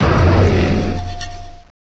sovereignx/sound/direct_sound_samples/cries/gouging_fire.aif at master
gouging_fire.aif